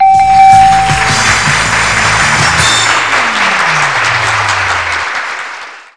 (dings; audience applause; MG-HSH win music plays)
sfxclapwin.wav